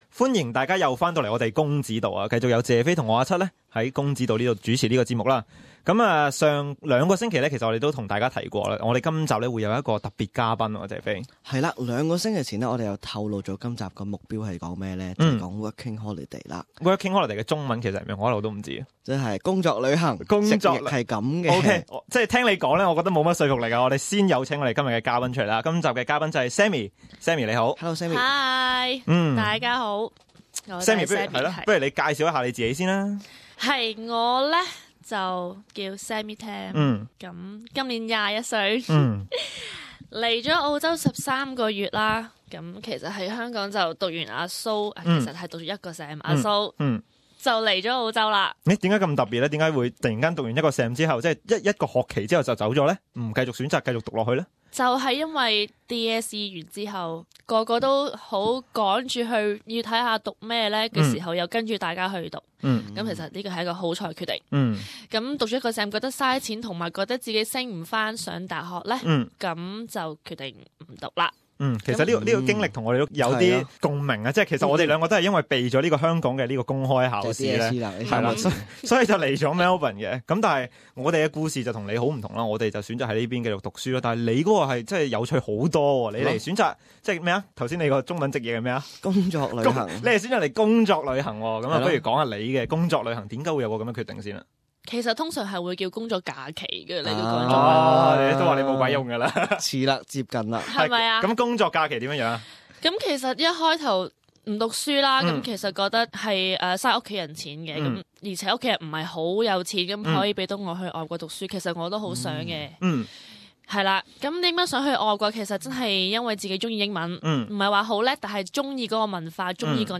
公子道| 访问背囊客 在澳洲的经验